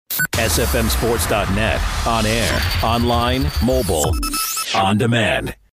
chats with Bayside football coaches and recaps the scores from a night of football